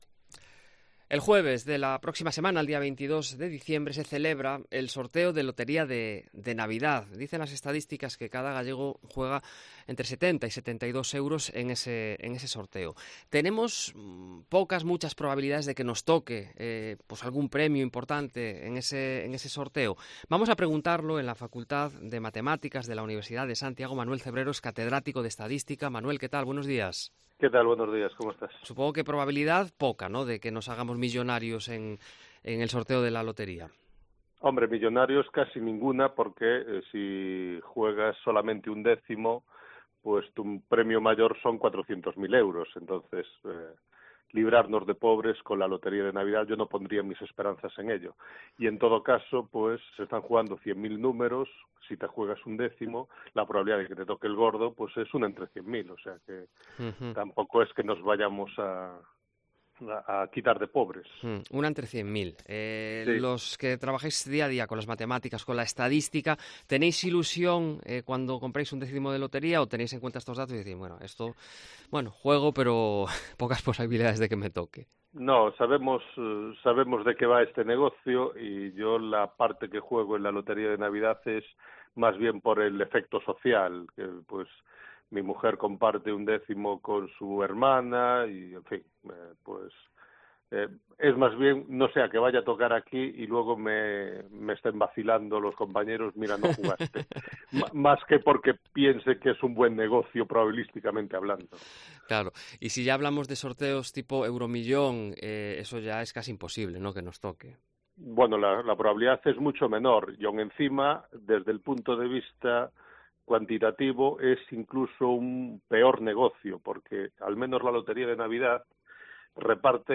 Se lo preguntamos a un catedrático de Estadística
"Yo juego unos 60 euros y me gustan las terminaciones en 5 y en 7", nos cuenta otro comprador.